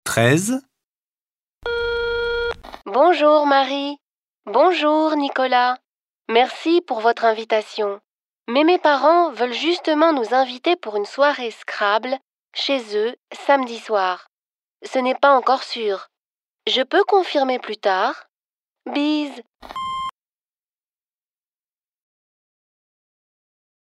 Folgende Hördialoge stehen zur Verfügung: